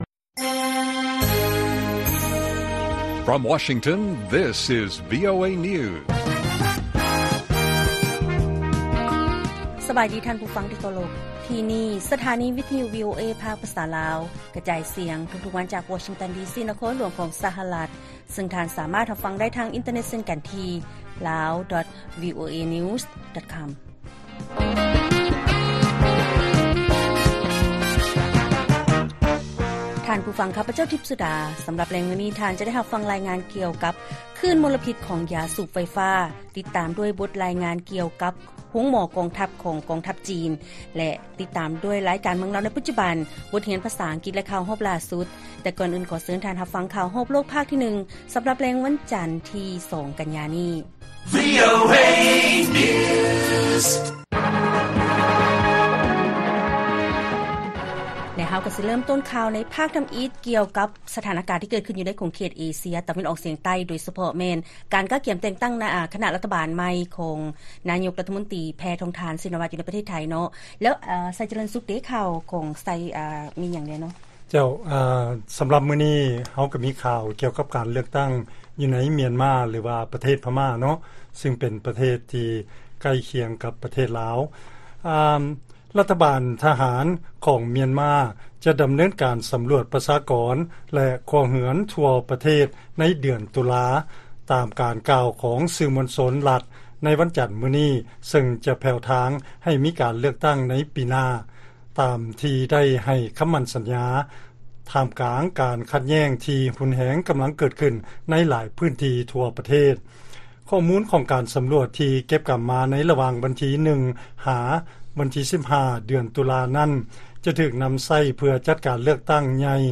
ລາຍການກະຈາຍສຽງຂອງວີໂອເອລາວ: ນາຍົກລັດຖະມົນຕີໄທ ຕຽມຈັດຕັ້ງຄະນະລັດຖະມົນຕີຂັ້ນສຸດທ້າຍ ໂດຍຈະນໍາເຂົ້າຮັບການອະນຸມັດຈາກກະສັດ